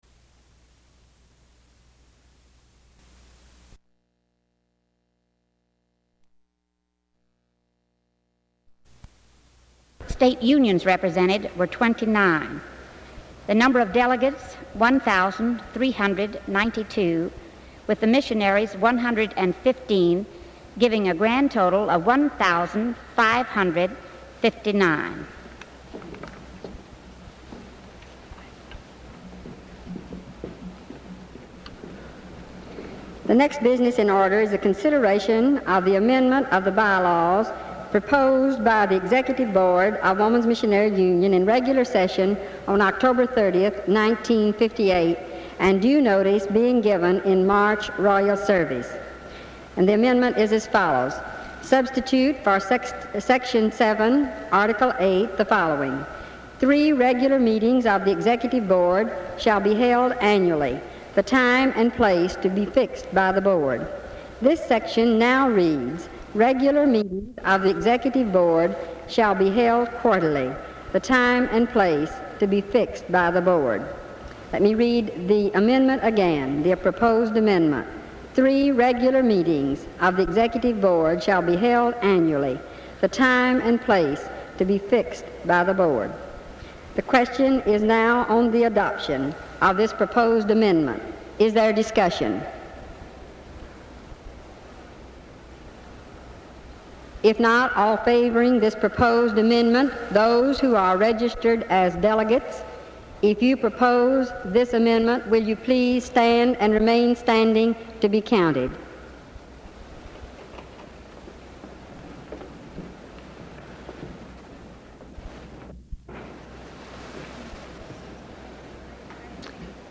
The 1959 WMU annual meeting was held May 18-19, 1959, in Louisville, Kentucky.